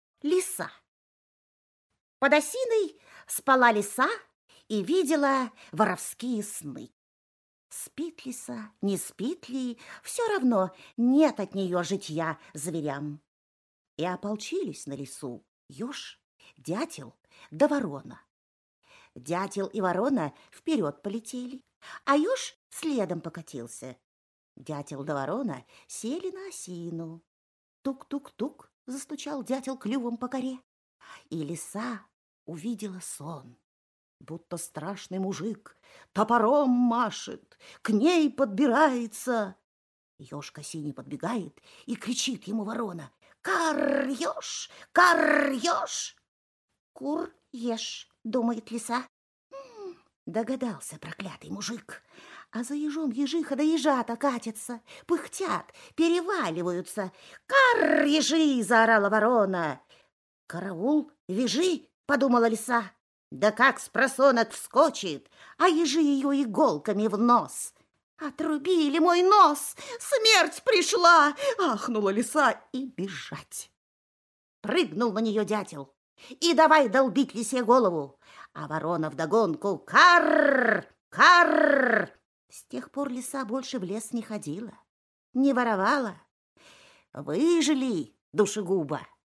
Лиса – Толстой А.Н. (аудиоверсия)